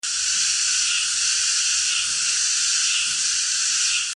蝉 知了 | 健康成长
zhiliao-sound.mp3